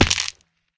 fallbig1.ogg